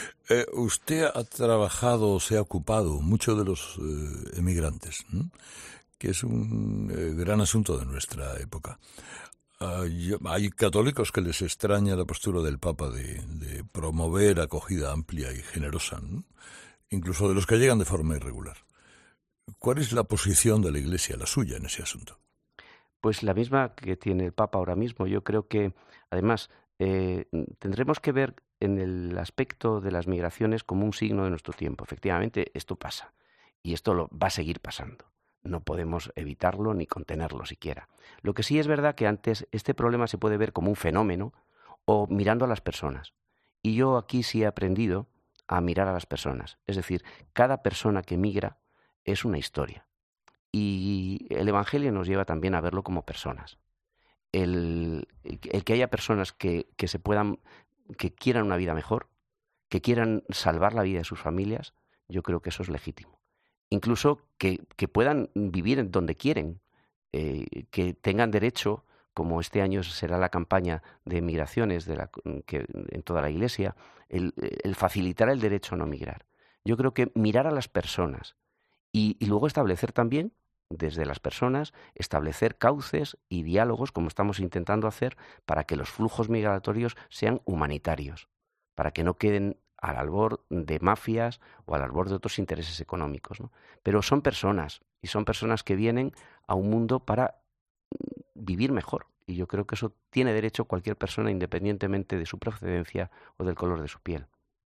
Escucha la entrevista de Carlos Herrera a José Cobo Cano, arzobispo de Madrid